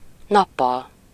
Ääntäminen
Ääntäminen US Tuntematon aksentti: IPA : /ˈdeɪz/ Haettu sana löytyi näillä lähdekielillä: englanti Käännös Ääninäyte Adverbit 1. nappal Substantiivit 2. napok Days on sanan day monikko.